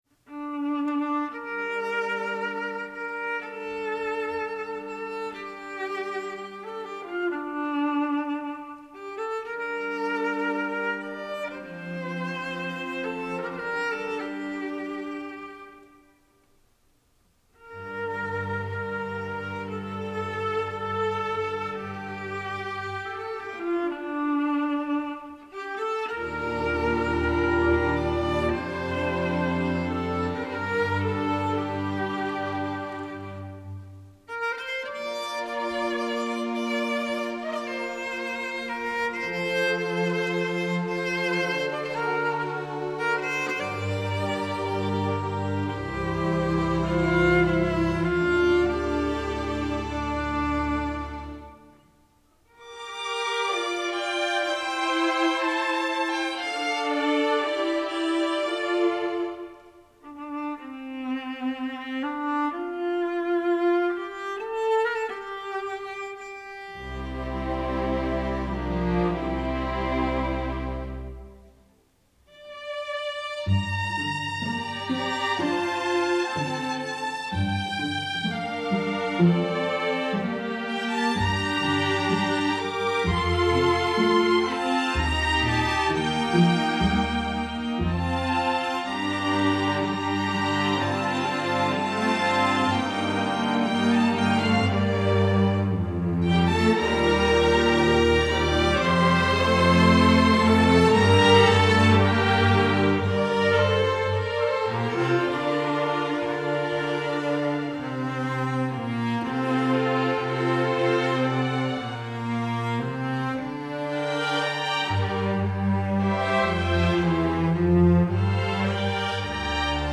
Composer: Traditional
Voicing: String Orchestra